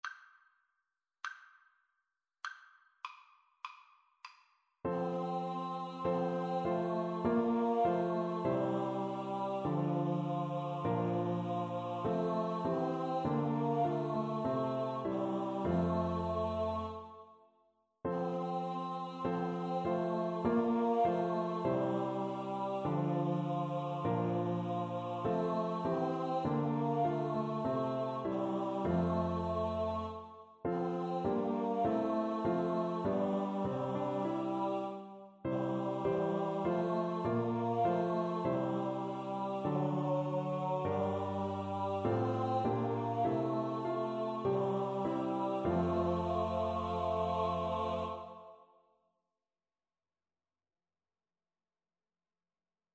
Free Sheet music for Choir (SATB)
4/4 (View more 4/4 Music)
F major (Sounding Pitch) (View more F major Music for Choir )
Christmas (View more Christmas Choir Music)